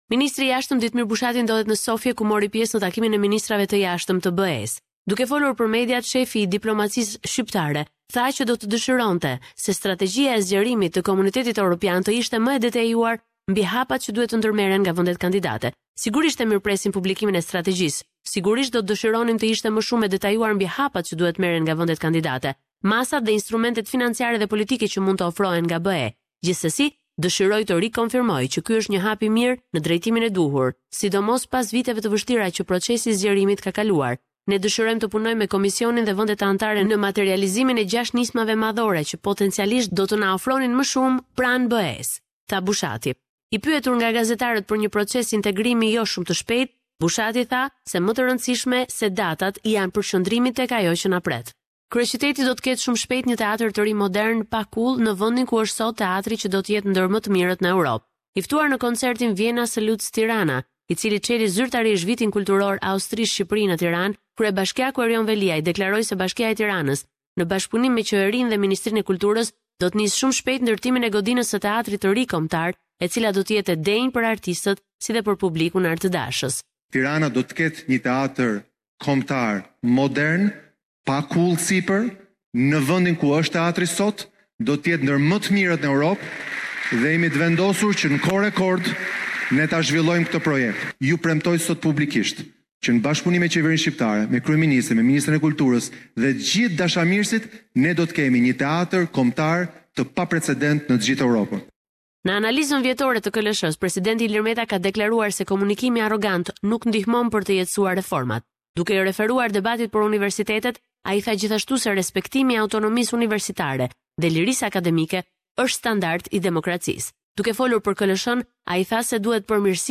Raporti i perjavshem me te rejat me te fundit nga Shqiperia.